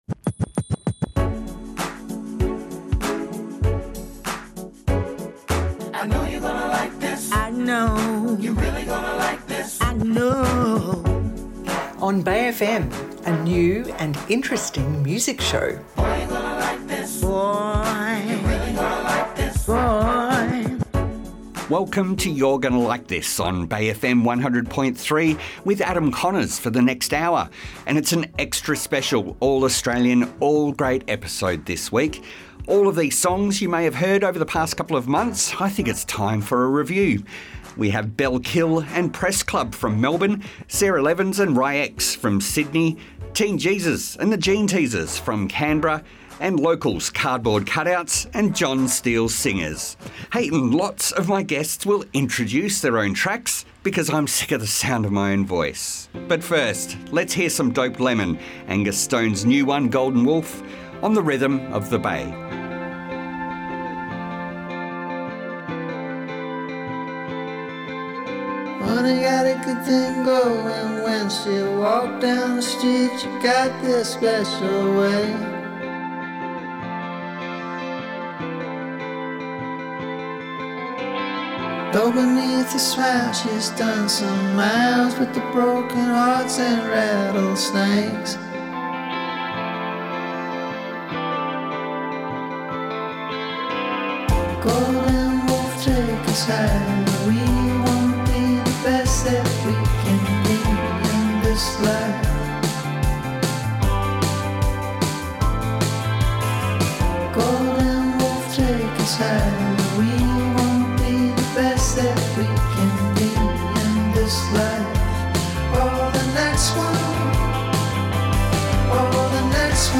Every song is Australian and everyone’s a winner.